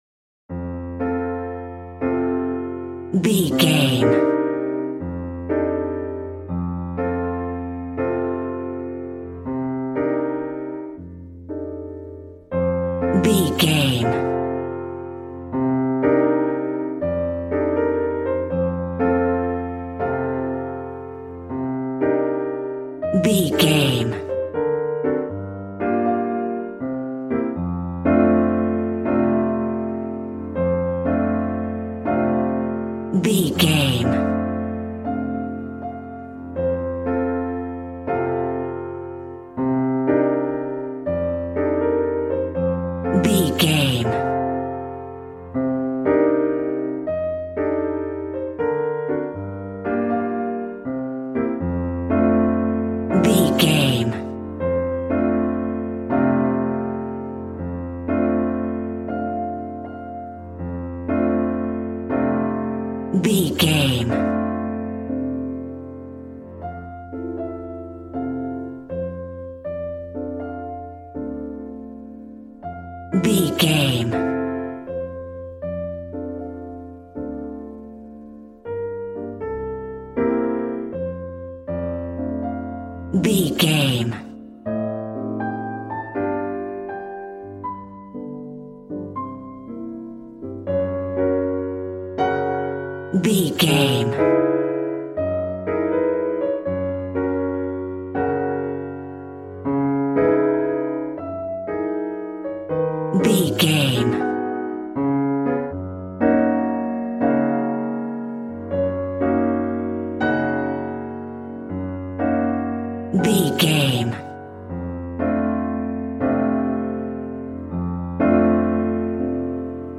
Ionian/Major
smooth
piano
drums